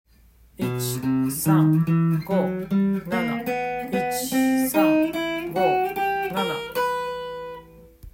度数練習
④も４和音に半音階でアプローチしています。